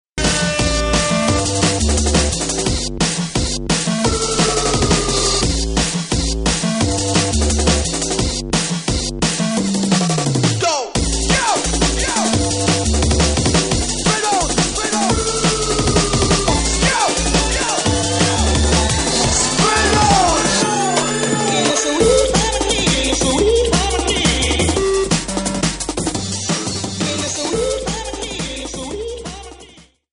Jungle Mix